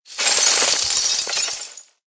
CHQ_VP_raining_gears.ogg